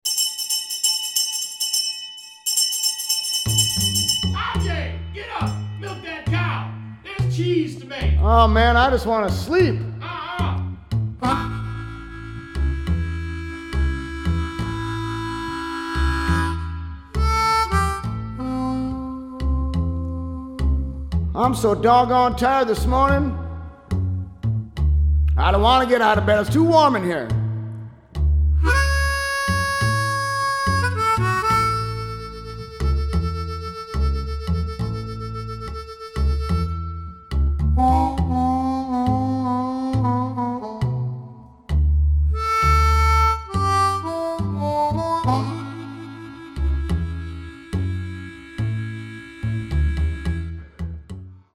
harmonica
Blues